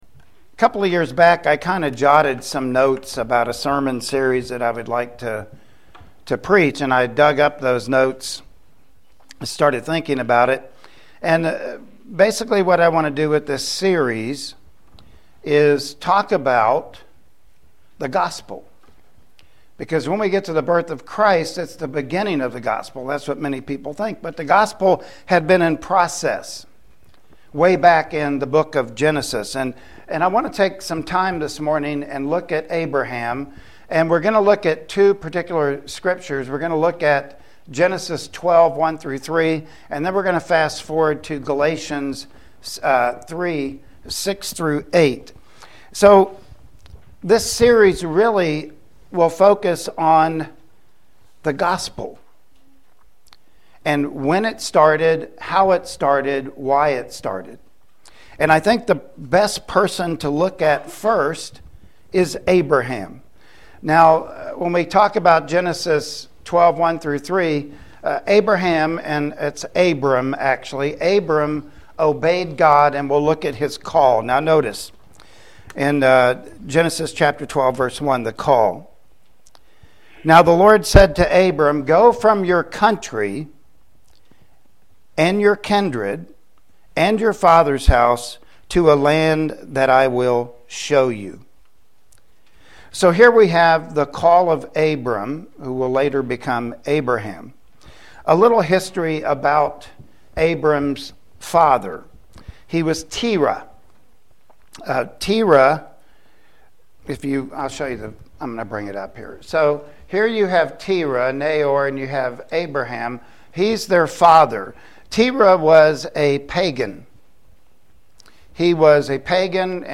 Christmas Passage: Genesis 12:1-3; Galatians 3:6-8 Service Type: Sunday Morning Worship Service Topics
Abraham-Sermon1.mp3